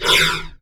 Heavy Breaths
BREATH3M.wav